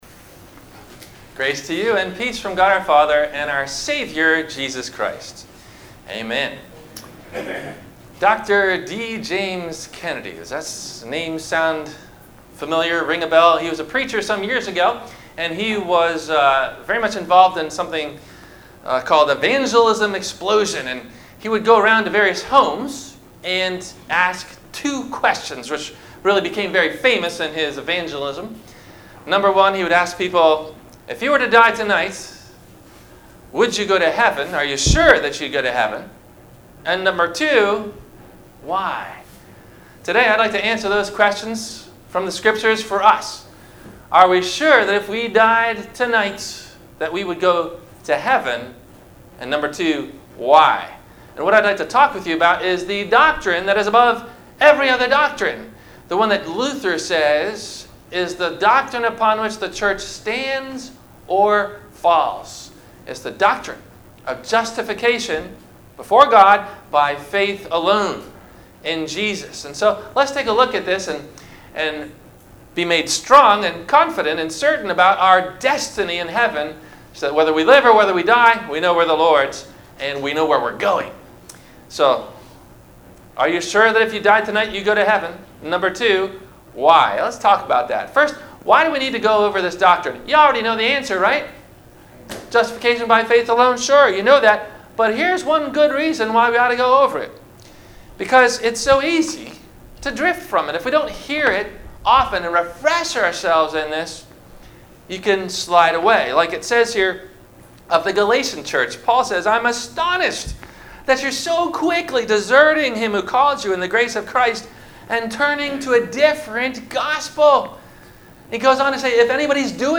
- Sermon - September 16 2018 - Christ Lutheran Cape Canaveral